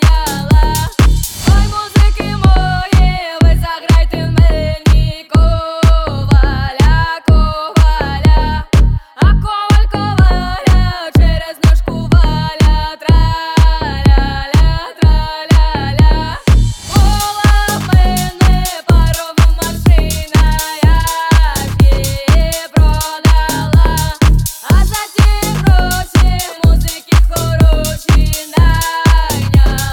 Жанр: Танцевальные / Русские
# Dance